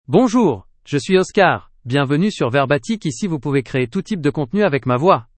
Oscar — Male French (France) AI Voice | TTS, Voice Cloning & Video | Verbatik AI
OscarMale French AI voice
Voice sample
Listen to Oscar's male French voice.
Oscar delivers clear pronunciation with authentic France French intonation, making your content sound professionally produced.